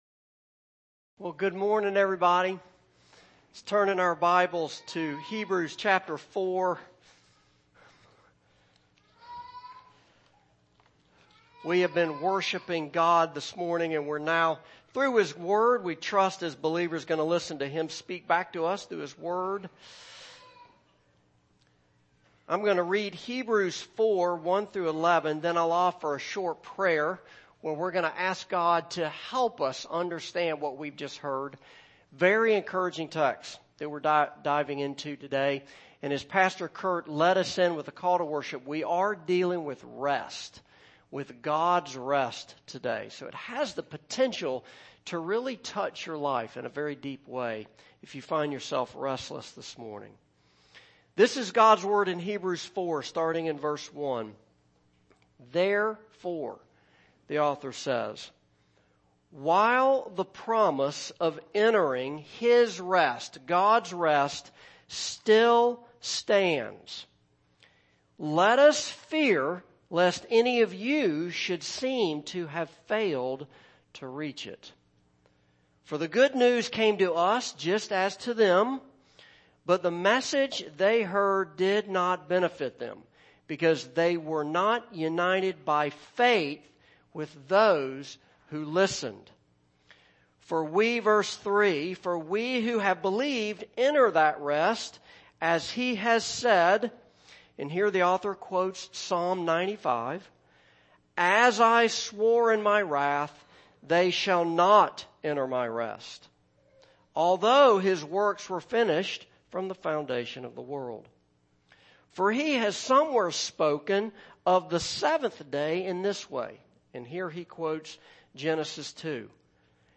Passage: Hebrews 4:1-11 Service Type: Morning Service